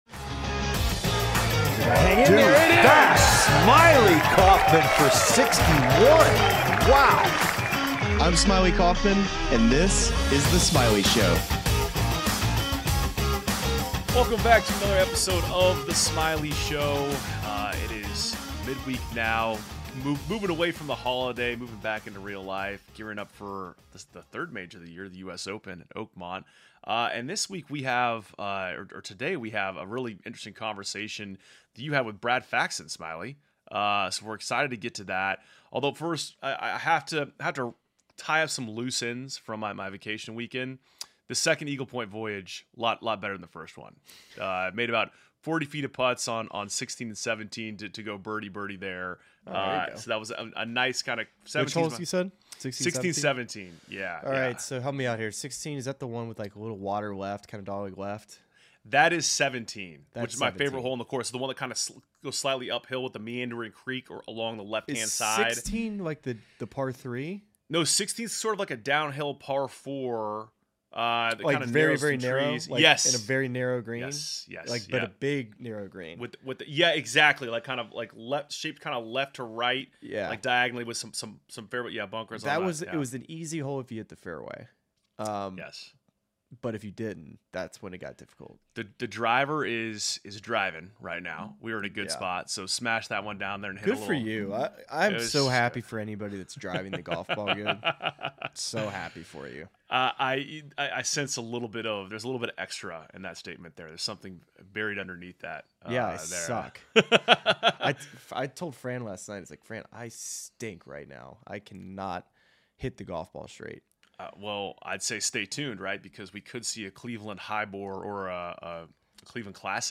Smylie Kaufman is joined by Brad Faxon, one of the greatest putters in golf history, to discuss his work with Rory McIlroy over the years.